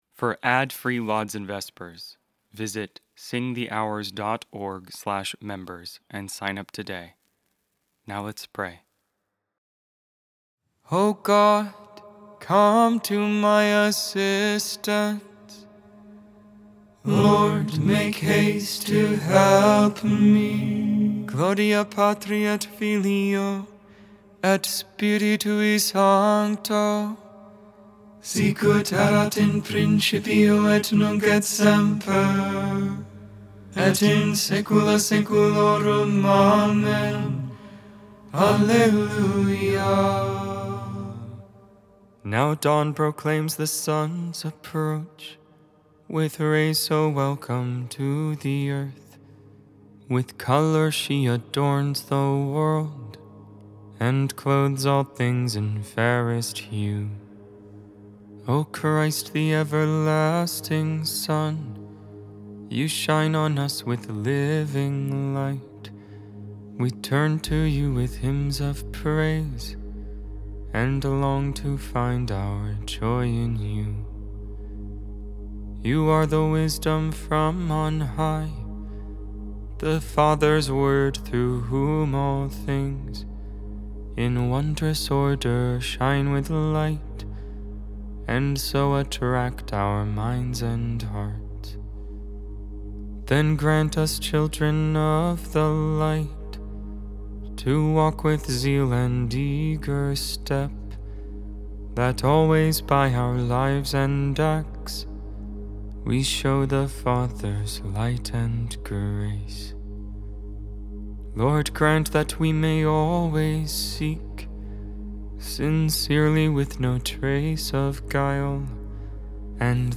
Music, Christianity, Religion & Spirituality